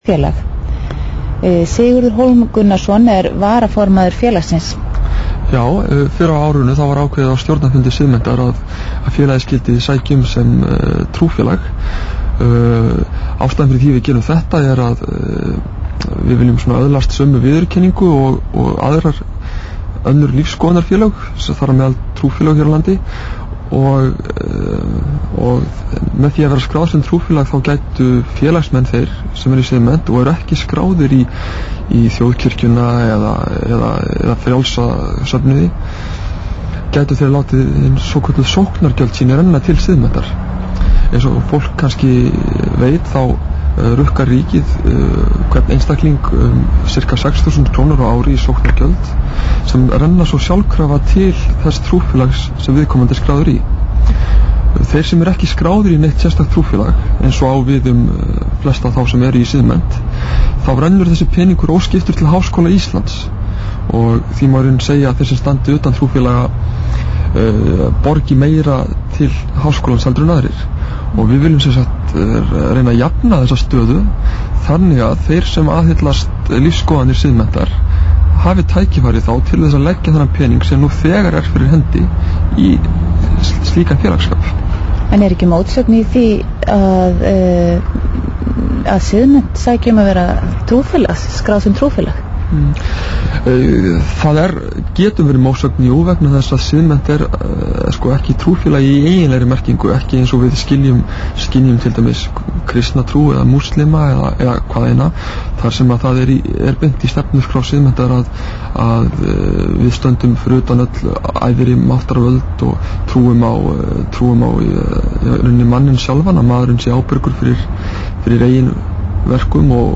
Vi�tal � R�V